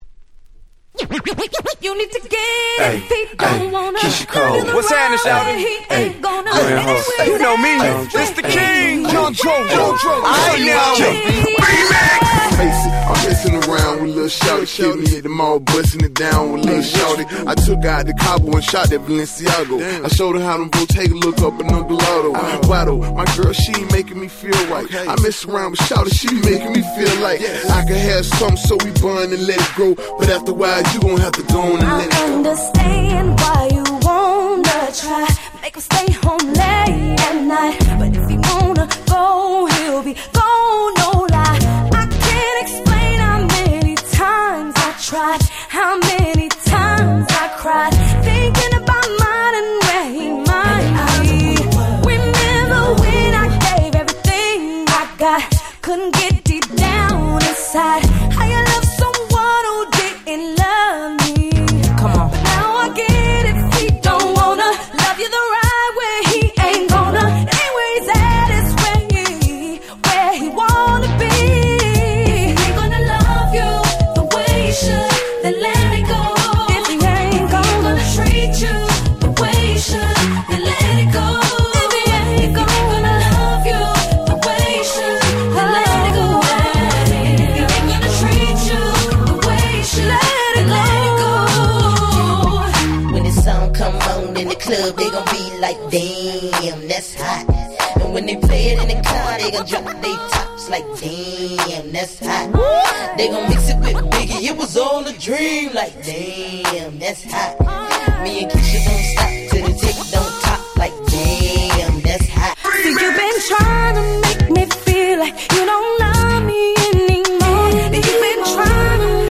07' Smash Hit R&B !!